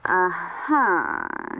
uh-huh.wav